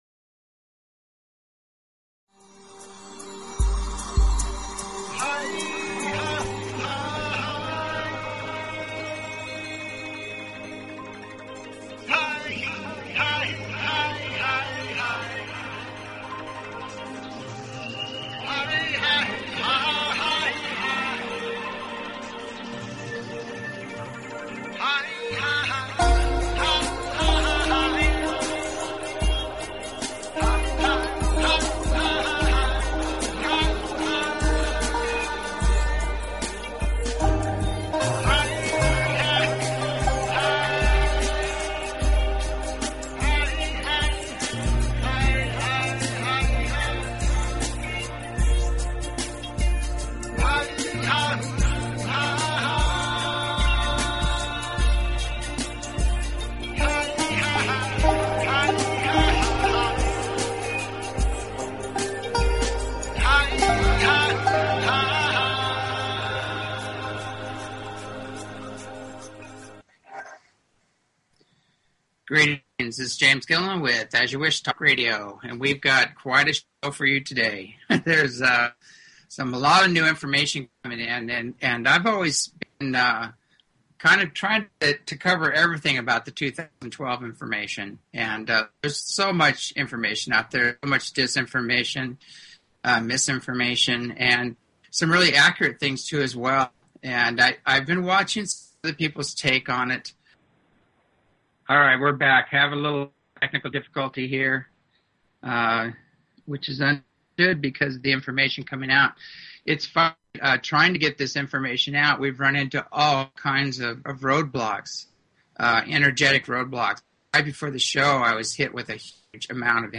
Talk Show Episode, Audio Podcast, As_You_Wish_Talk_Radio and Courtesy of BBS Radio on , show guests , about , categorized as
Callins, due to trouble with equipement 2 hours of awesome information.
As you Wish Talk Radio, cutting edge authors, healers & scientists broadcasted Live from the ECETI ranch, an internationally known UFO & Paranormal hot spot.